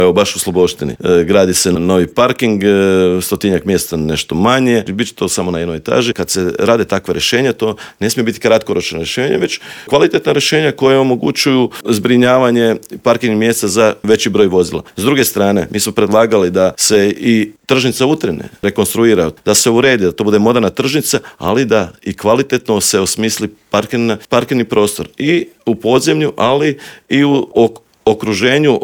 ZAGREB - U novom izdanju Intervjua Media servisa gostovao je predsjednika HDZ-a Novi Zagreb Istok i državnog tajnika u Ministarstvu poljoprivrede Tugomir Majdak s kojim smo prošli teme od gorućih problema u Novom Zagrebu, preko najavljenog prosvjeda u Sisku protiv industrijskih megafarmi i klaonice pilića pa sve do ovisnosti Hrvatske o uvozu hrane.